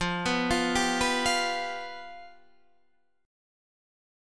F Suspended 2nd Flat 5th